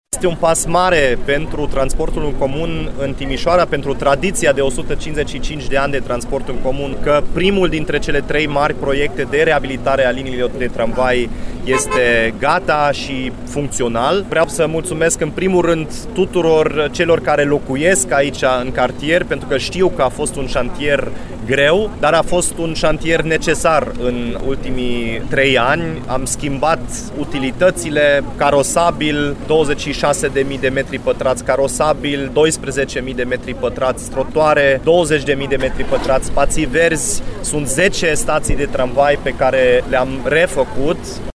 Primarul Dominic Fritz le-a mulțumit, în primul rând, localnicilor din zonă, pentru că au fost nevoiți să suporter șantierul.